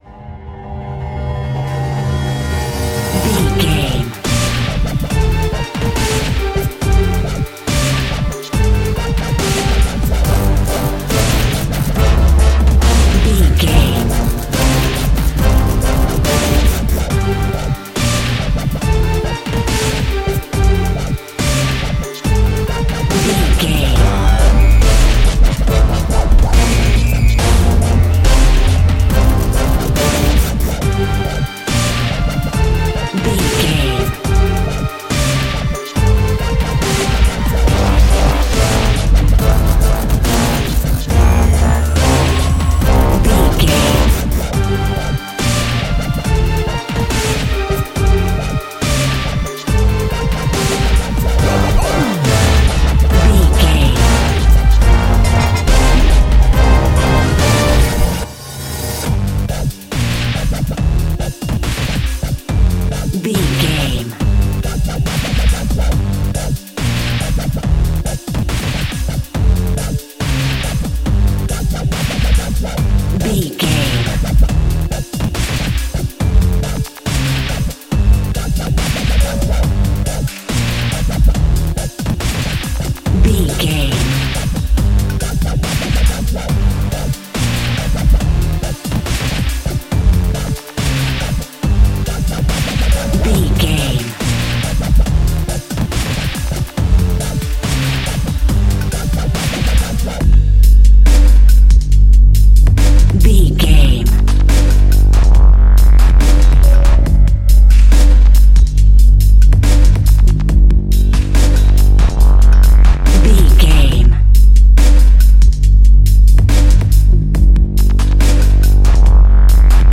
Aeolian/Minor
drum machine
synthesiser
orchestral
orchestral hybrid
dubstep
aggressive
energetic
intense
strings
drums
bass
synth effects
wobbles
epic